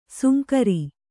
♪ sunkari